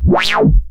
tekTTE63018acid-A.wav